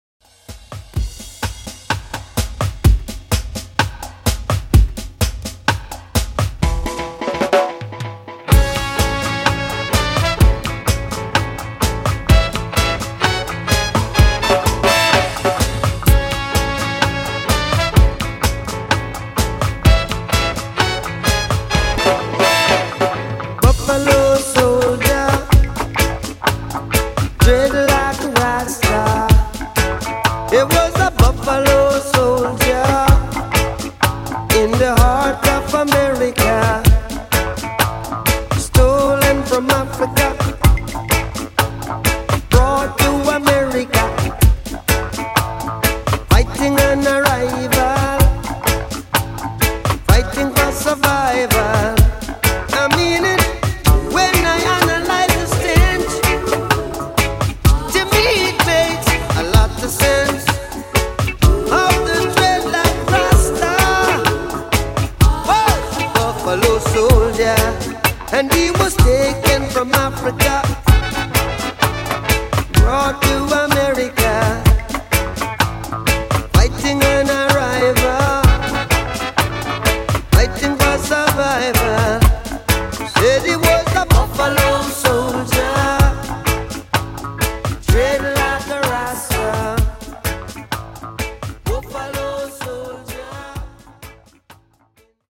Redrum Reggae)Date Added